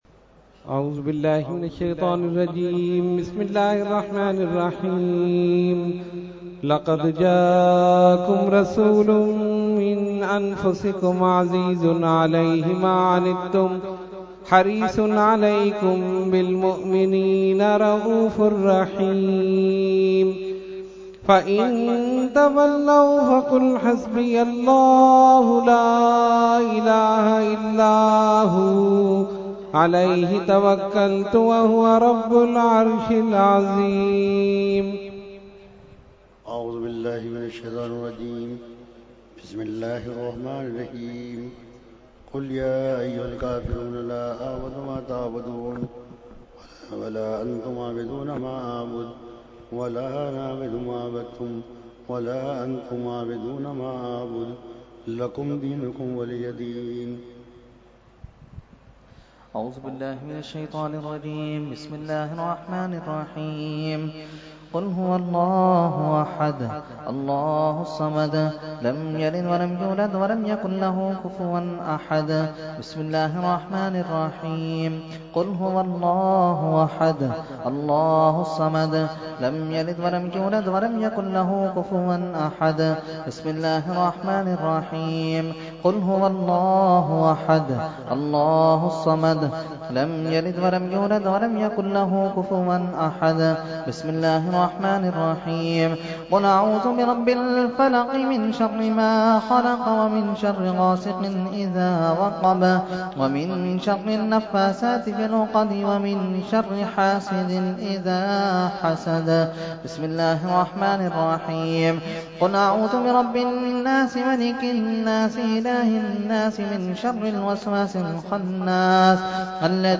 Fatiha Dua – Urs Qutbe Rabbani 2018 – Dargah Alia Ashrafia Karachi Pakistan
14-Fatiha and Dua.mp3